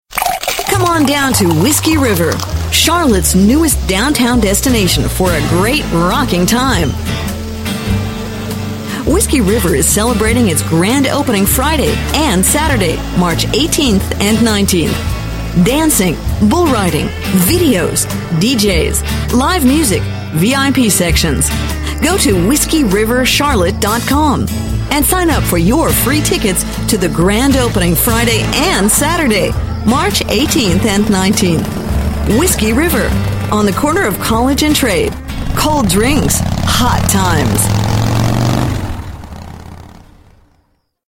Sprecherin amerikanisch englisch für Werbung, Sport, Kultur und Musiksendungen, Schulungsfilme, Dokumentationen, PC-Spiele, Zeichentrickfilme
middle west
Sprechprobe: Sonstiges (Muttersprache):